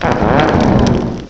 sovereignx/sound/direct_sound_samples/cries/pignite.aif at master
pignite.aif